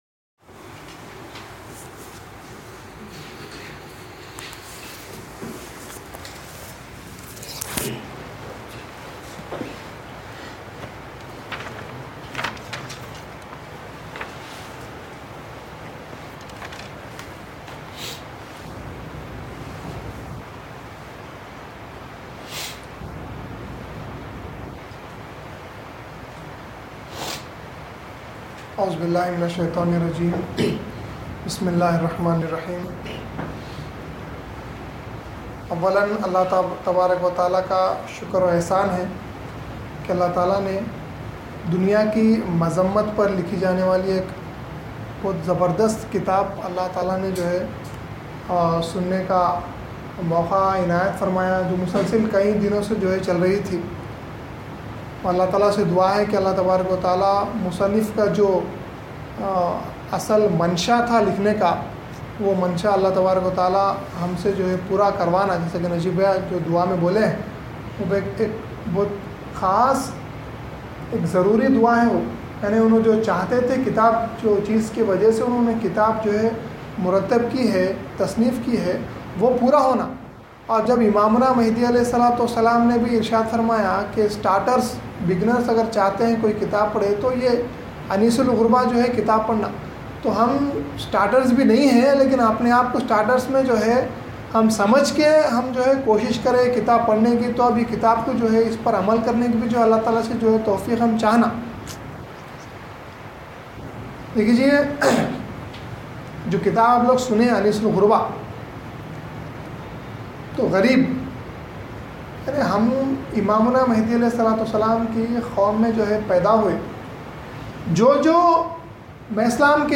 Special Bayaans – Teachings Of Mehdi AHS
Anees-Ul-Gurba-Special-Bayaan.mp3